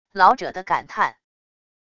老者的感叹wav音频